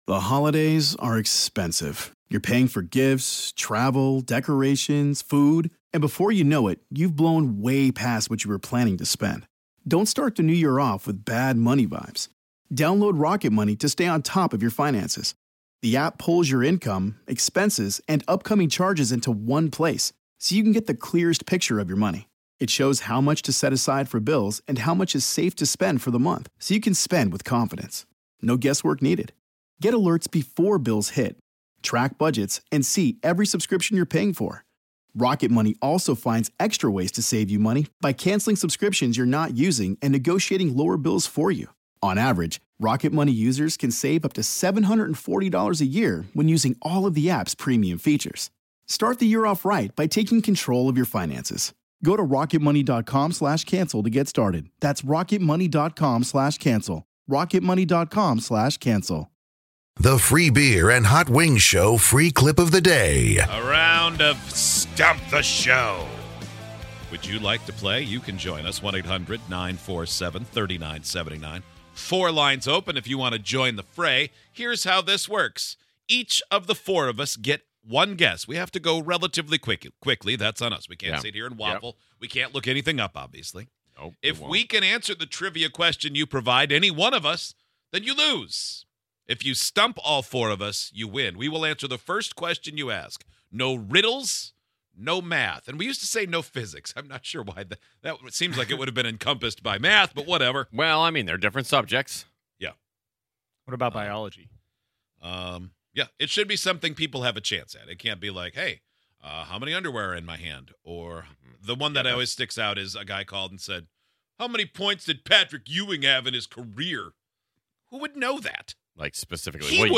On today's show, we played stump the show. What question had all of us laughing out loud?